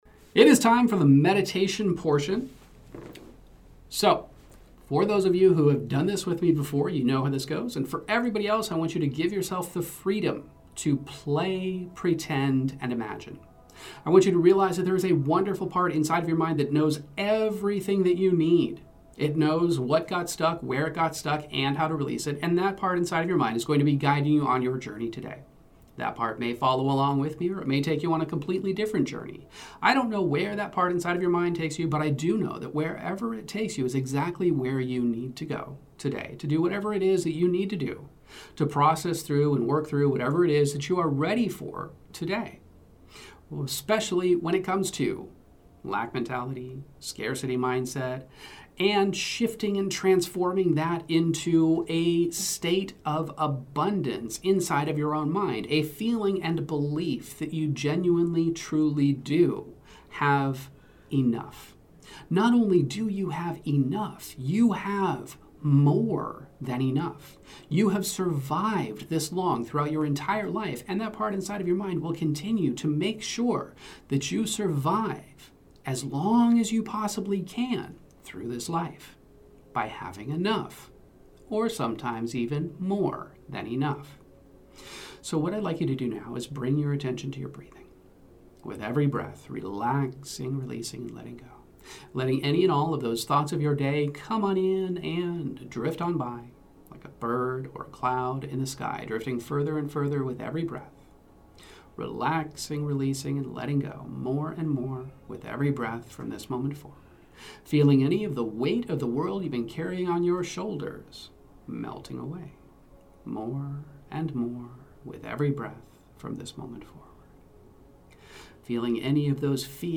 This guided hypnosis meditation takes you on a transformational journey from a scarcity mindset to a world brimming with abundance.